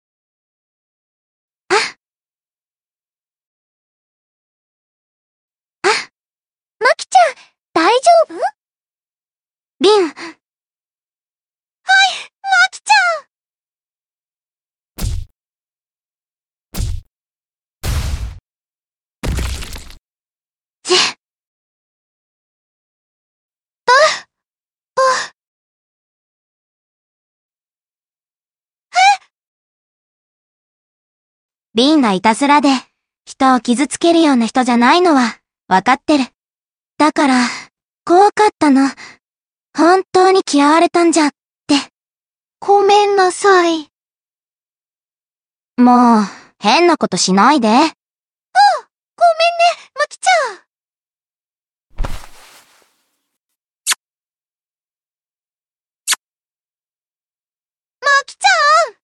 注意：過去のラ！板ｓｓで自分の好きなものを、最近はやりの中華ＡＩツールに読ませてみました。
暗い声色が少ないから悲しんでてもテンション高いな笑
音程の調整はできないんだよ